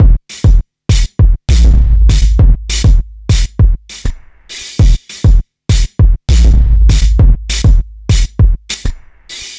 noise beat 100bpm 03.wav